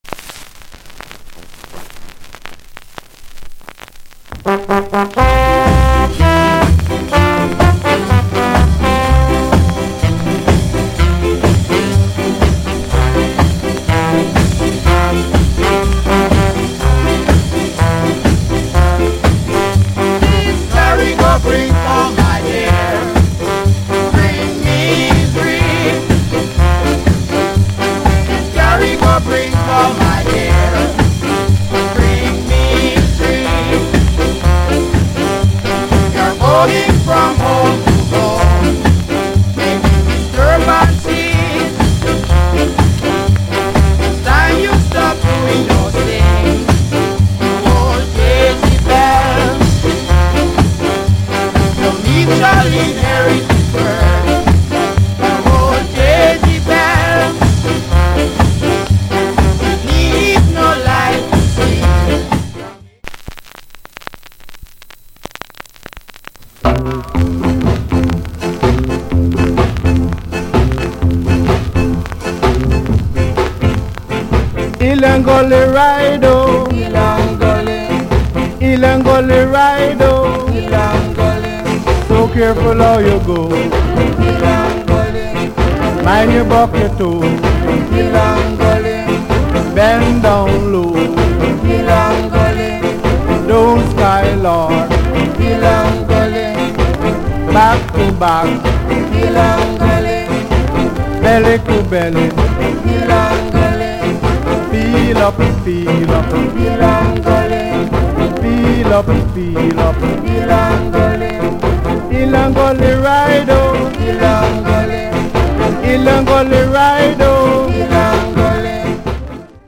Genre Ska / Group Vocal